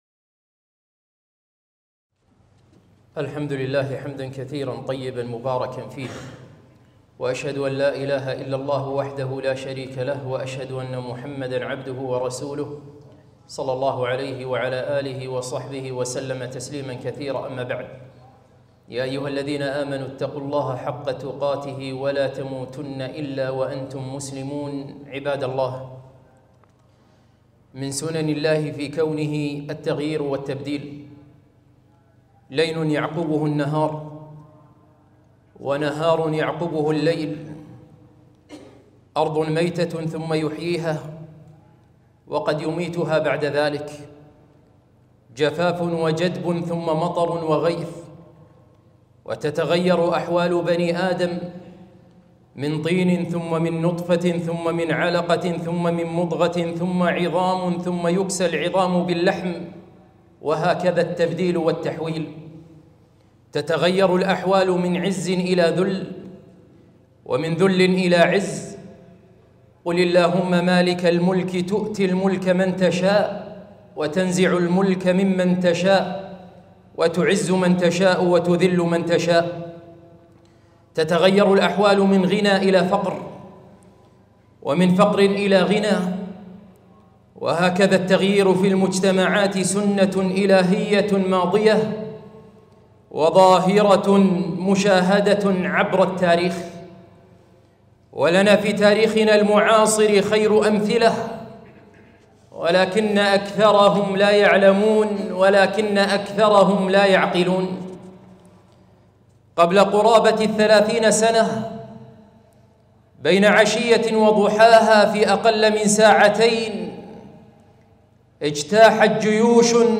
خطبة - ولنا في حكم طالبان عبرة